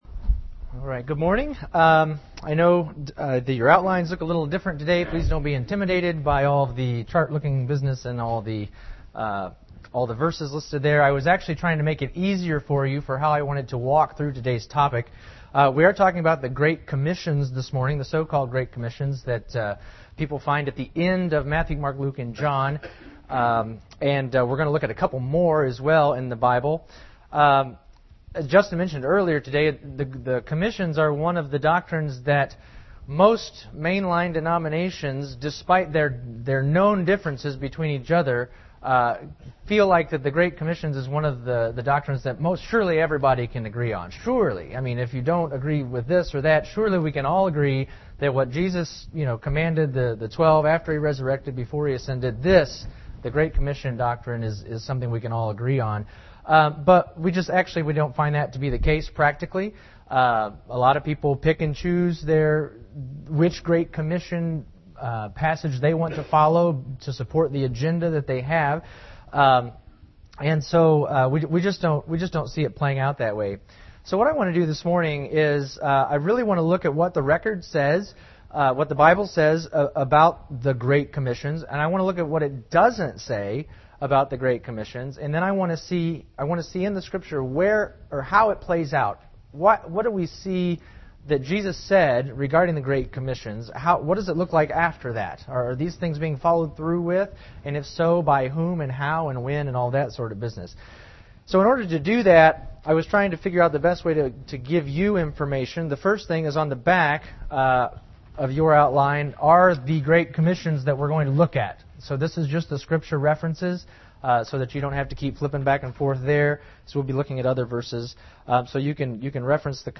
This lesson provides an overview of the so-called “Great Commissions”. Learn about what they actually say, what they do not say, how the apostles carried each of them out, and how it all changed with a new commission given to Paul.